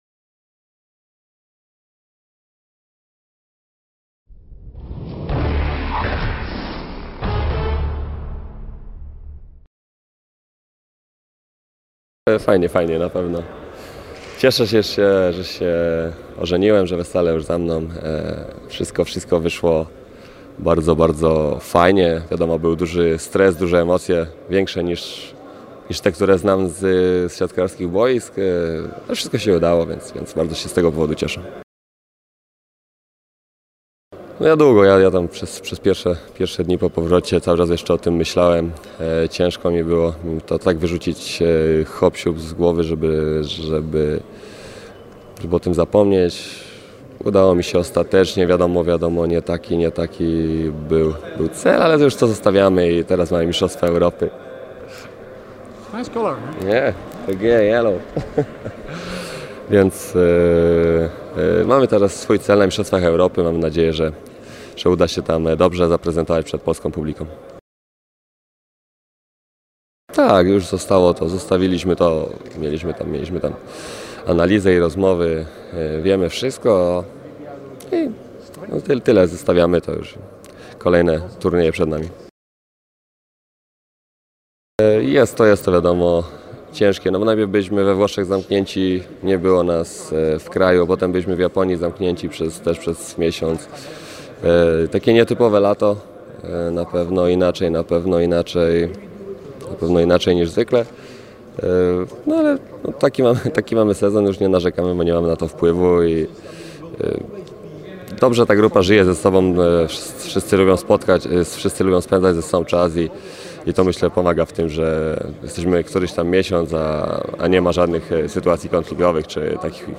Siatkarz PGE Skry nie tylko walczył w igrzyskach olimpijskich, Lidze Narodów i uczestniczył w przygotowaniach do mistrzostw Europy, ale również… ożenił się! Zapraszamy na wywiad z naszym środkowym.